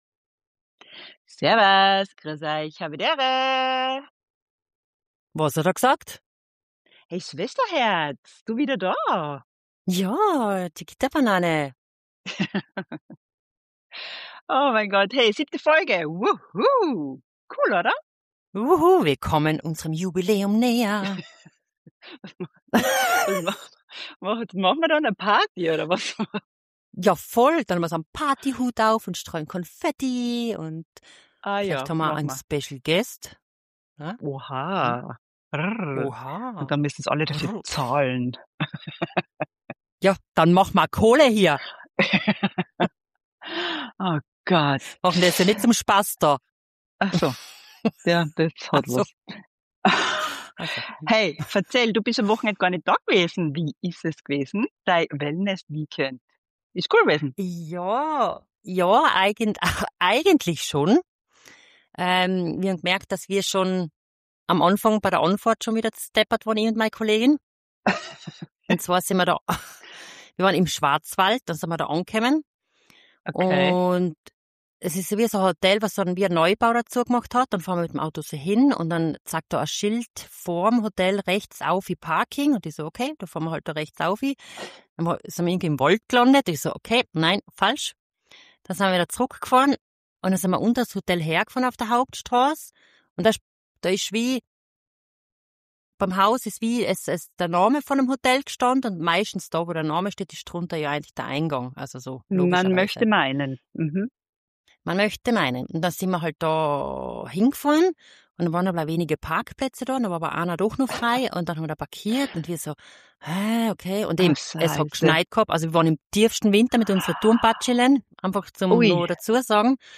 2 Schwestern aus Österreich reich seit über 20 Jahren in der Schweiz. das Wellness Weekend beginnt schon wieder fantastisch, bei Ikea verschätzt sich glaubs jeder mit den Grössen, der wuschelige Goldfisch mitem Schwanzl in der Cola Plastikflasche , unser...